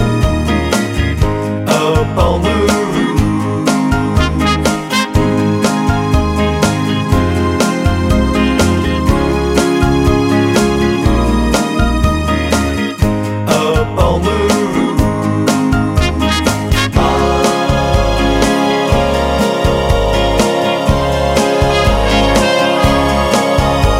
no Backing Vocals Soul / Motown 2:35 Buy £1.50